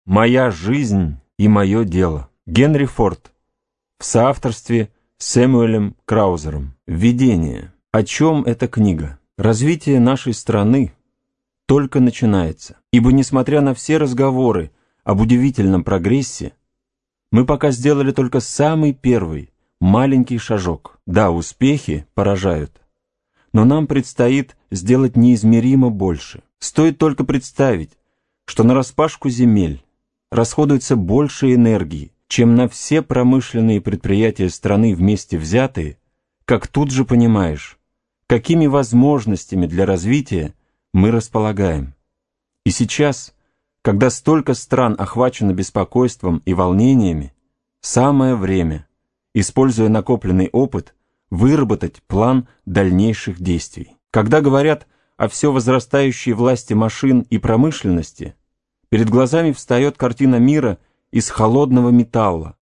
Аудиокнига Моя жизнь и мое дело | Библиотека аудиокниг